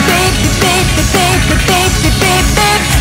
Play Layer - Beep Beep Beep - SoundBoardGuy
Play, download and share Layer – Beep beep beep original sound button!!!!
layer-beep-beep-beep.mp3